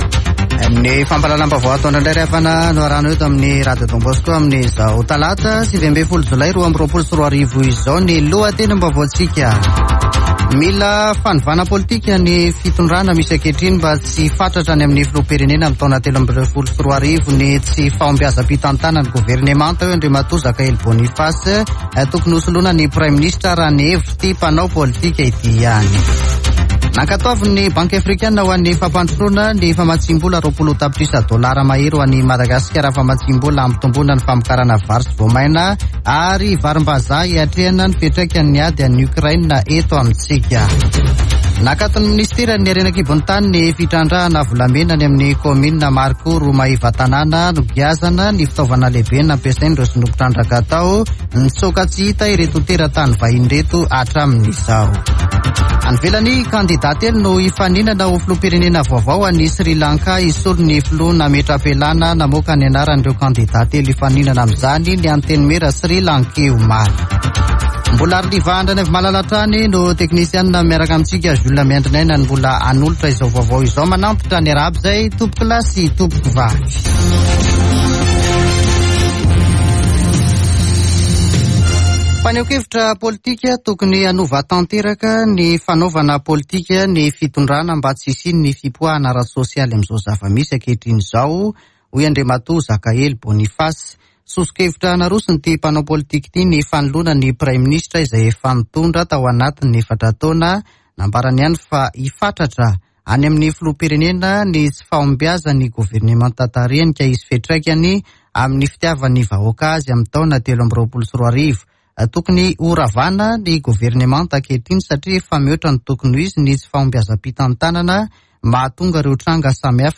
[Vaovao antoandro] Talata 19 jolay 2022